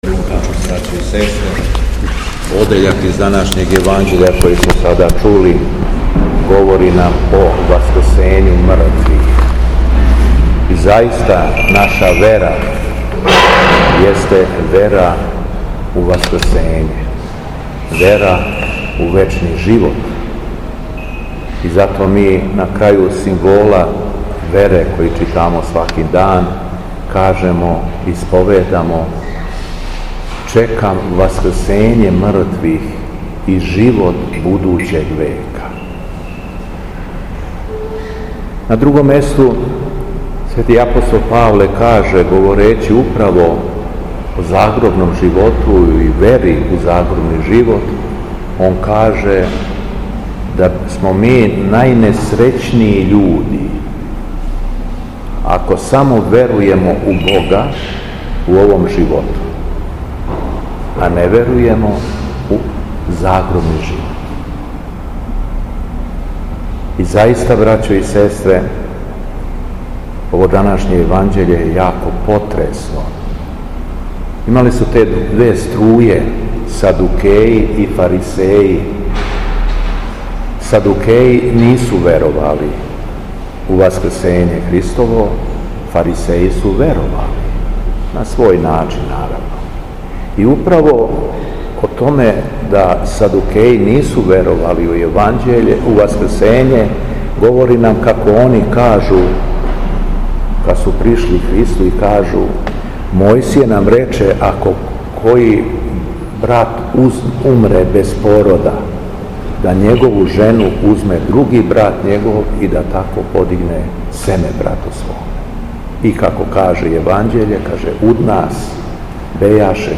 У петак, 30. августа 2024. године, када се наша Света Црква молитвено сећа светог мученика Патрокла и мученика Мирона, Његово Високопреосвештенство Митрополит крагујевачки г. Јован служио је Свету Архијерејску Литургију у храму Свете Петке у крагујевачком насељу Виногради уз саслужење братства овога...
Беседа Његовог Високопреосвештенства Митрополита шумадијског г. Јована
После прочитаног јеванђелског зачала, Високопреосвећени Митрополит се обратио беседом сабраном народу: